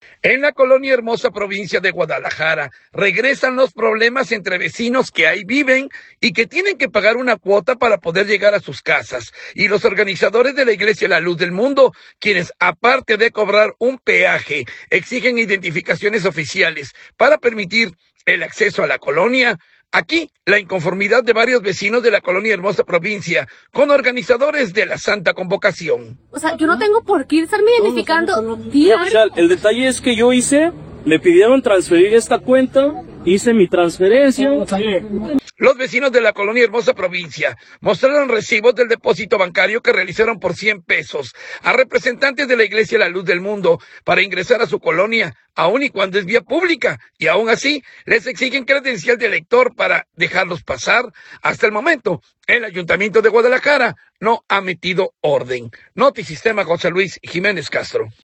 En la colonia Hermosa Provincia de Guadalajara regresan los problemas entre vecinos que ahí viven y que tienen que pagar una cuota para poder llegar a sus casas, y los organizadores de la Iglesia La Luz del Mundo, quienes, aparte de cobrar un peaje, exigen identificaciones oficiales para permitir el acceso a la colonia. Aquí la inconformidad de varios vecinos de la colonia Hermosa Provincia con organizadores de la Santa Convocación.